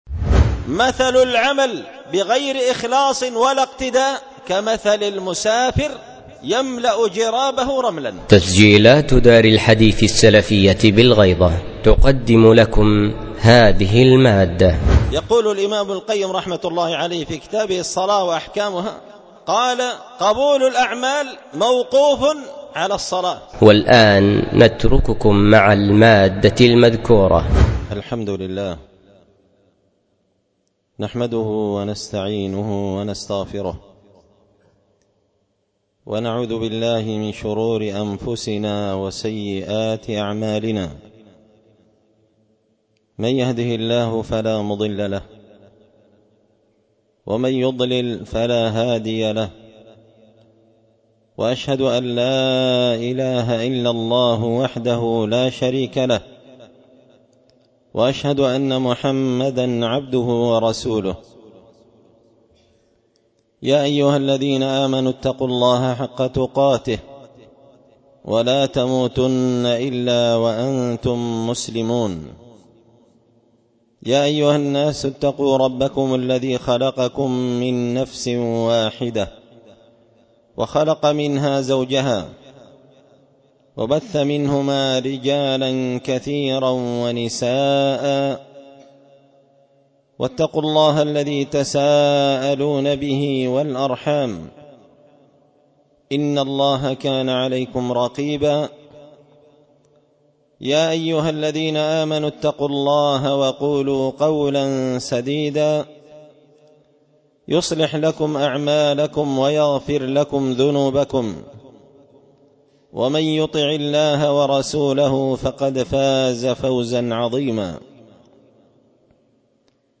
خطبة جمعة بعنوان
ألقيت هذه الخطبة بدار الحديث السلفية